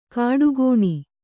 ♪ kāḍu gōṇi